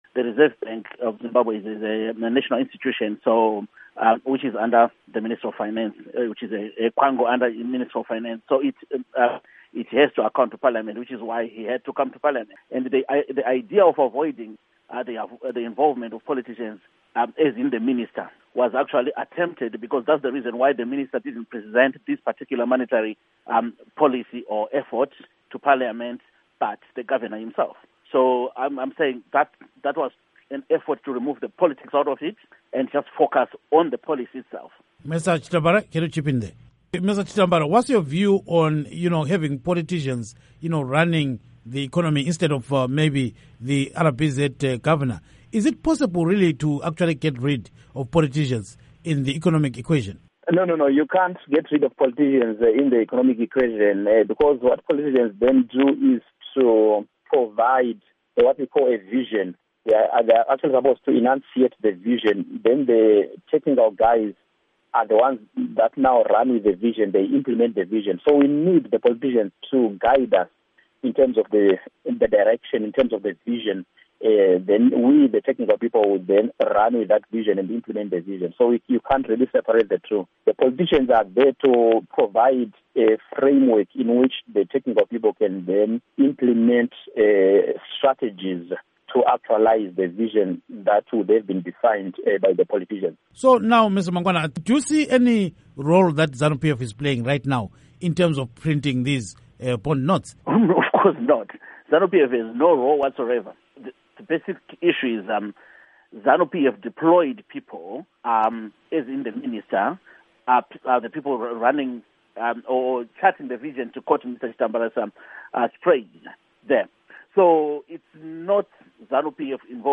Zanu PF Activist And Economist Discuss Role of Politicians in Fiscal Matters